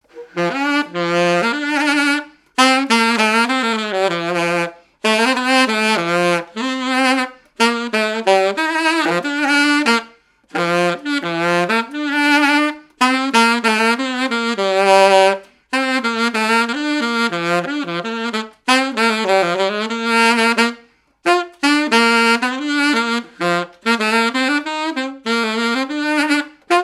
Marche de noce
Saint-Martin-Lars
activités et répertoire d'un musicien de noces et de bals
Pièce musicale inédite